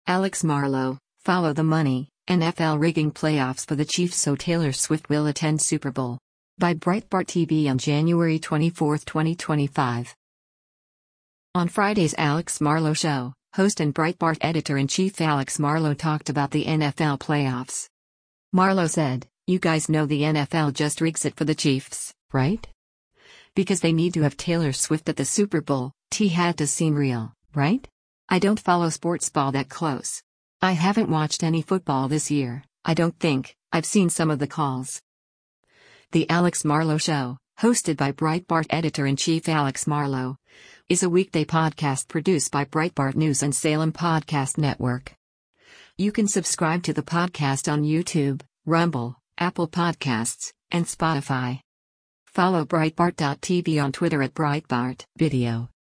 On Friday’s “Alex Marlow Show,” host and Breitbart Editor-in-Chief Alex Marlow talked about the NFL playoffs.